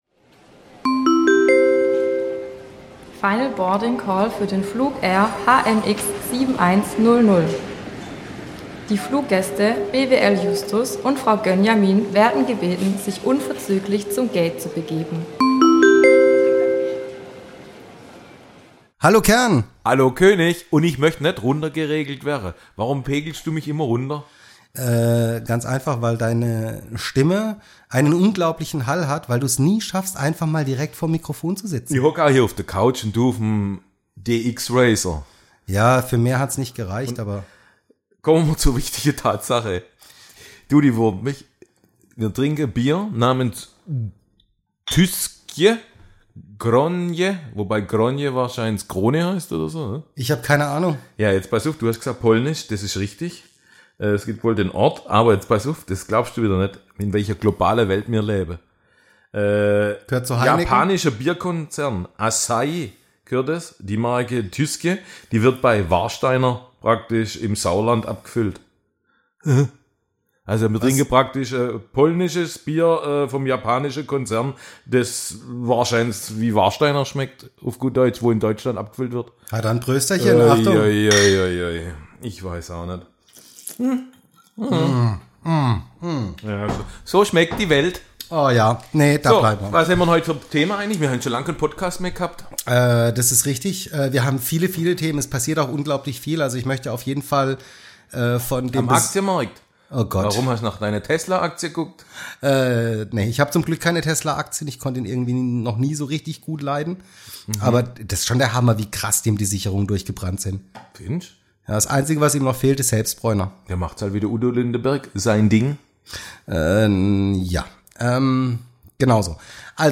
im Gespräch
das ganze wird live über Eutelsat auf der polnischen Bierfrequenz gesendet.